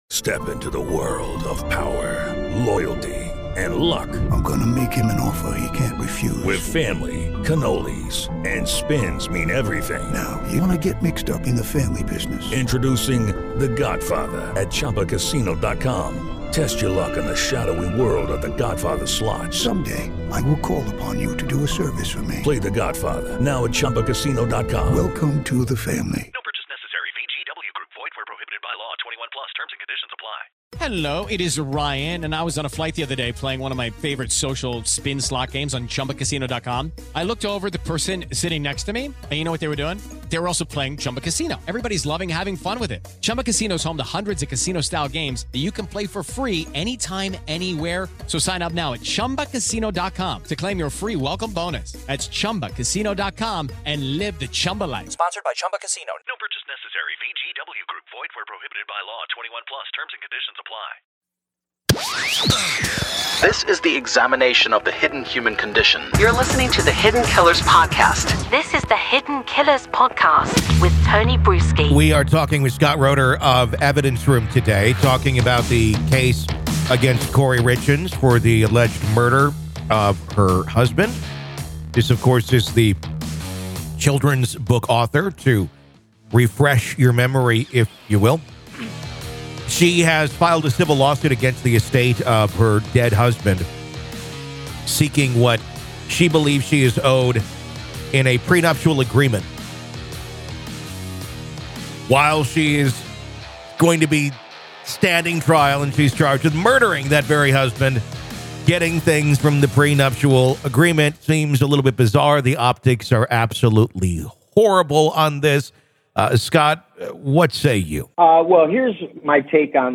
They discuss the ethical and legal implications of such a scenario, analyzing the legal boundaries that might affect her ability to access the funds. It's an engaging conversation that brings to light the complexities and potential loopholes in the law when it comes to crime-related financial disputes.